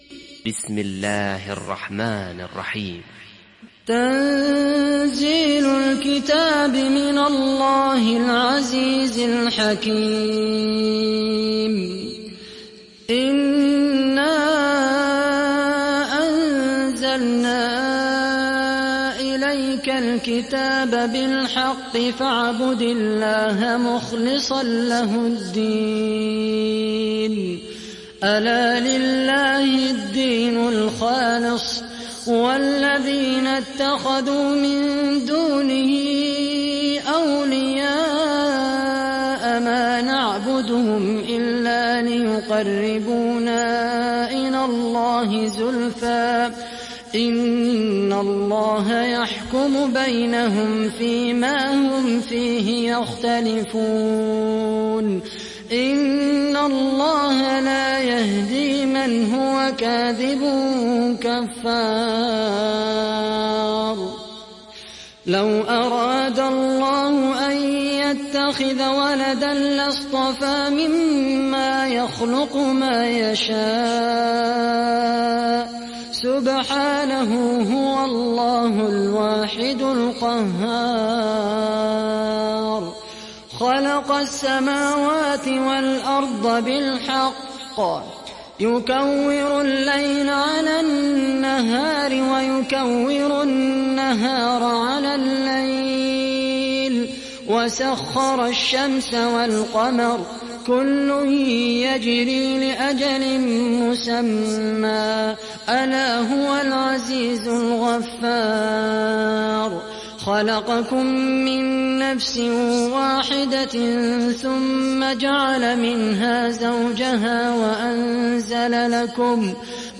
সূরা আয-যুমার ডাউনলোড mp3 Khaled Al Qahtani উপন্যাস Hafs থেকে Asim, ডাউনলোড করুন এবং কুরআন শুনুন mp3 সম্পূর্ণ সরাসরি লিঙ্ক